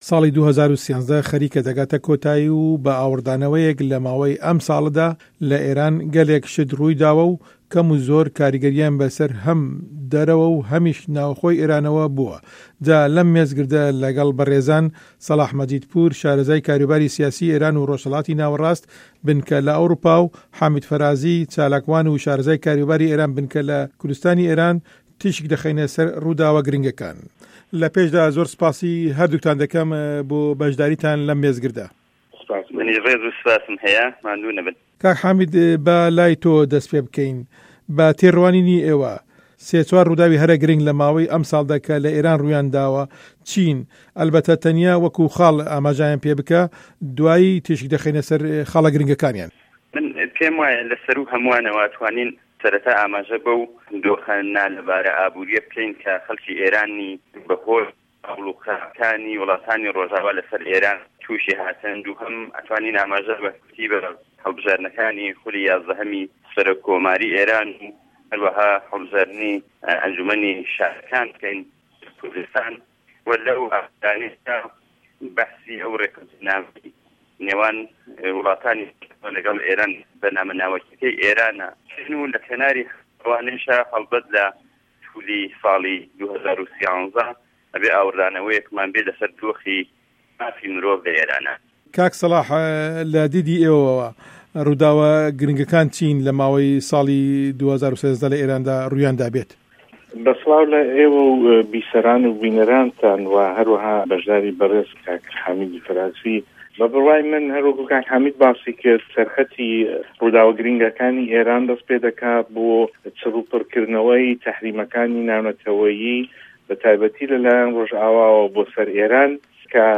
مێزگرد: ڕووداوه‌ گرنگه‌کانی سـاڵی 2013 له‌ ئێراندا